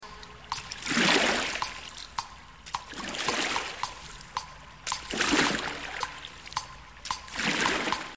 دانلود آهنگ سه بعدی 9 از افکت صوتی طبیعت و محیط
جلوه های صوتی